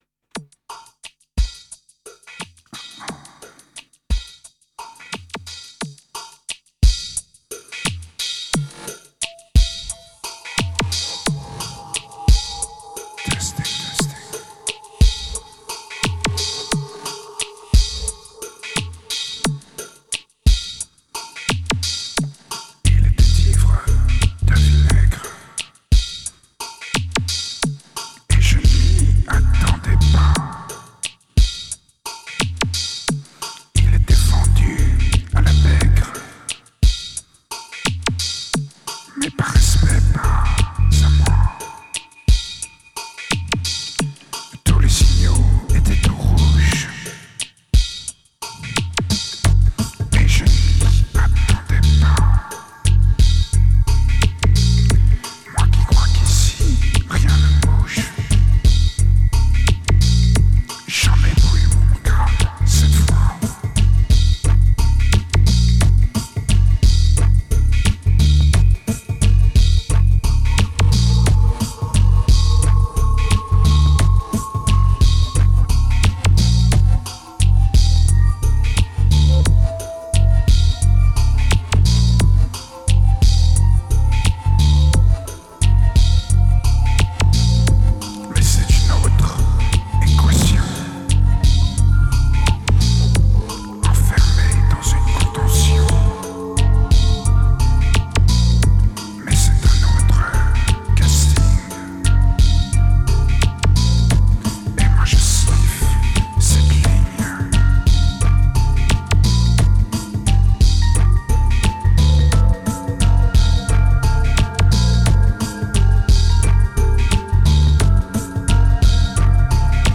It's raw, it's dirty and it's lame, but are you up to it ?
2039📈 - 57%🤔 - 88BPM🔊 - 2010-09-03📅 - 93🌟